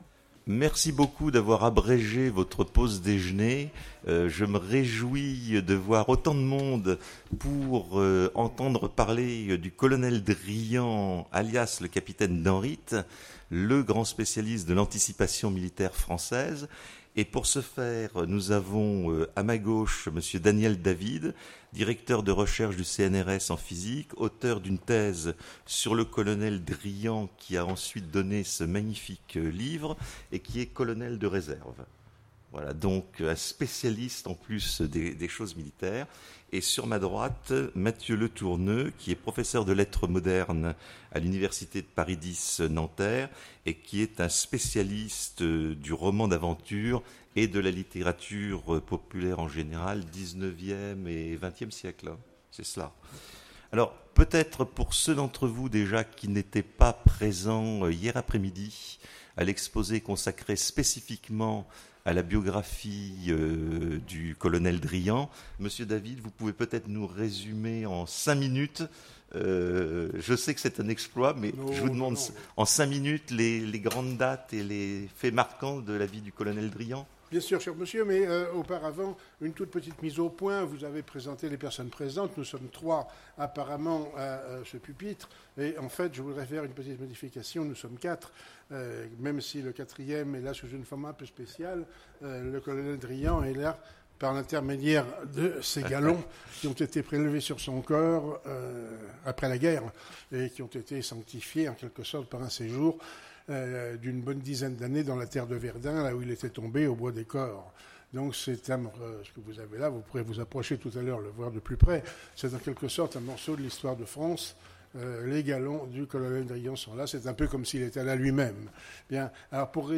Imaginales 2016 : Conférence Colonel Driant, Capitaine Danrit